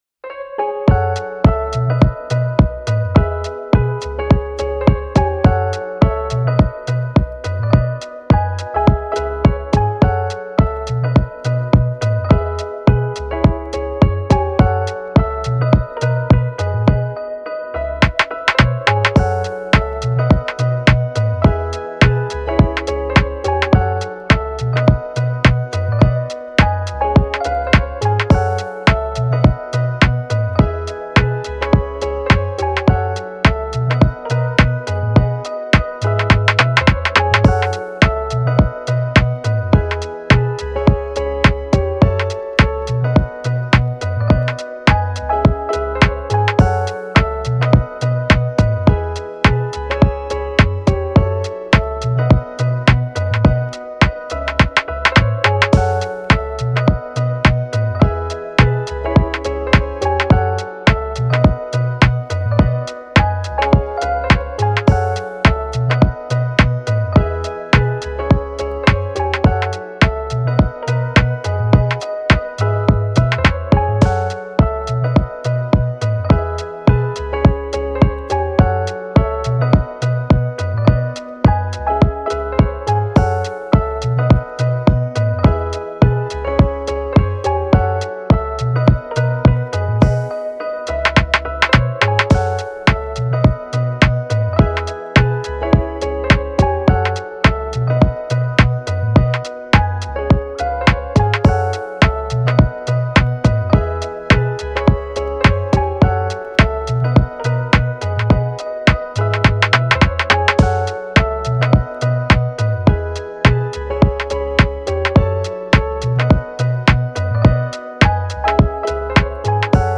チル・穏やか
明るい・ポップ